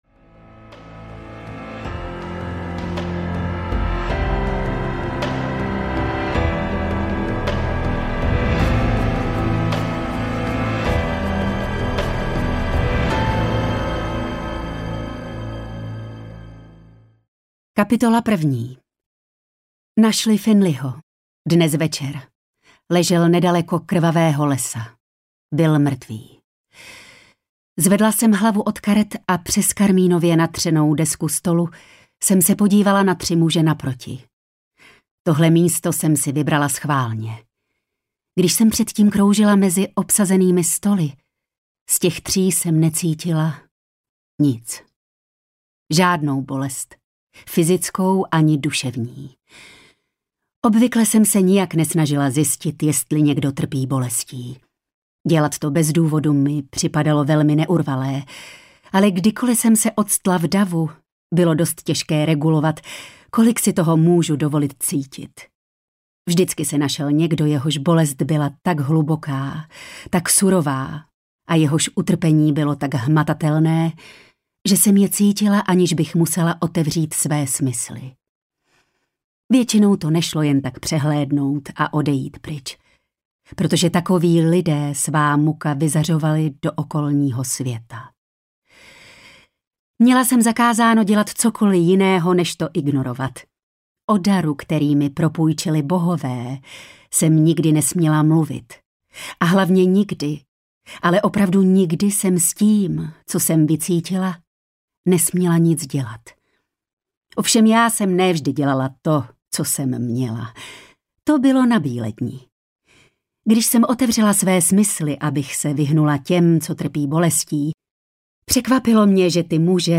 Z krve a popela audiokniha
Ukázka z knihy